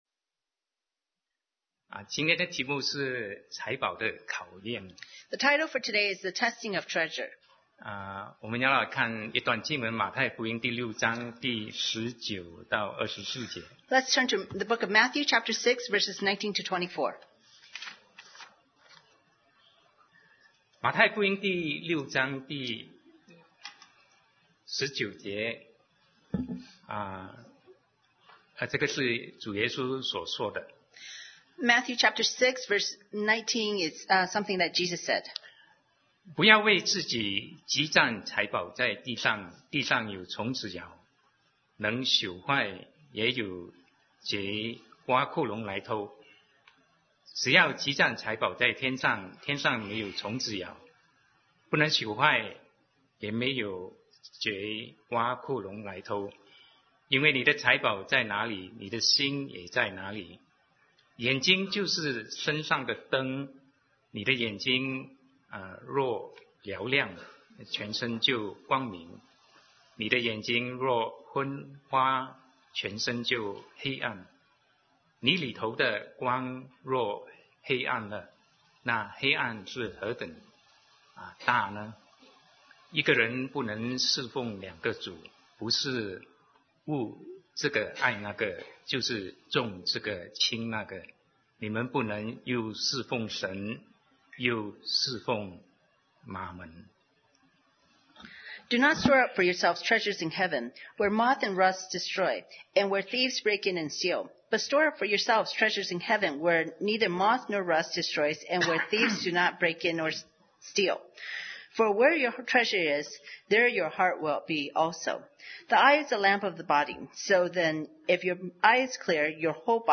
Sermon 2019-08-18 The Testing of Treasure